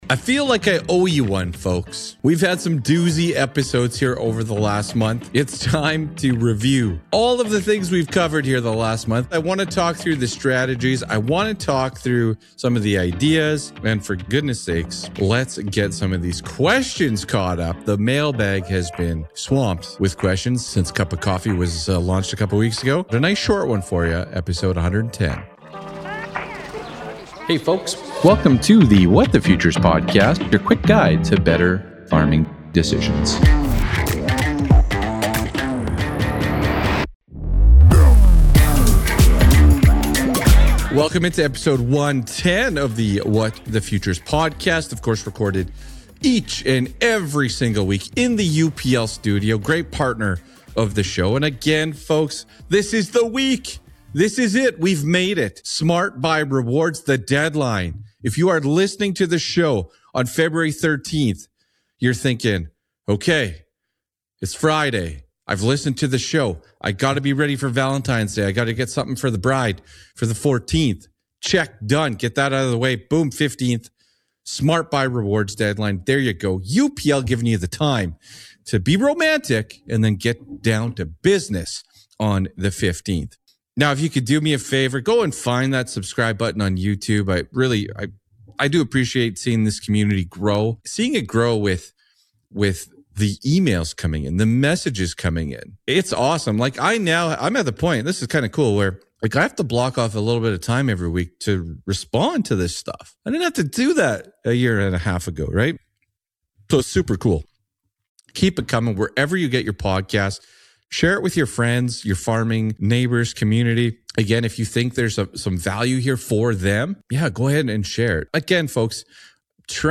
Recorded from the UPL studio, the episode touches on important dates like the Valentine's Day reminder for farmers and the Smart Buy rewards deadline.